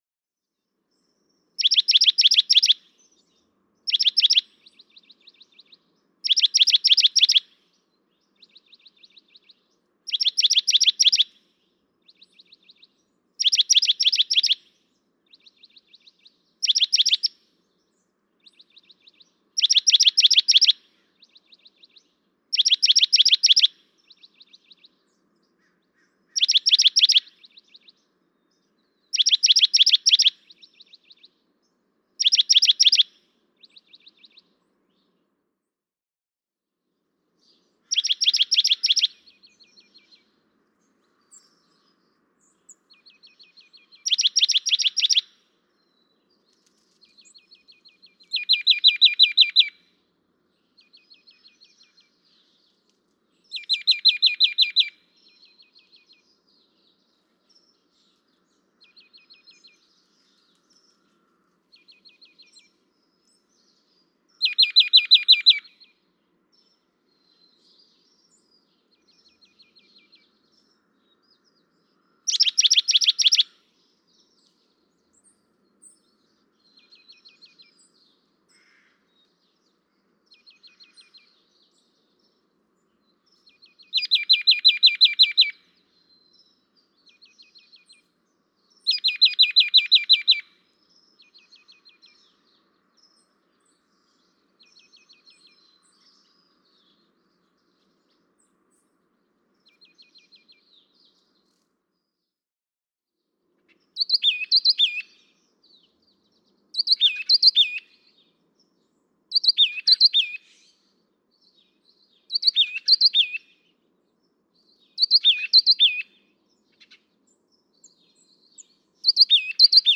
Oak titmouse
♫239. Song matching by two oak titmice; in these three excerpted passages from a much longer session, hear how the male in the background uses the same song as the male in the foreground.
Los Osos, California.